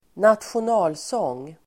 Uttal: [²natsjion'a:lsång:]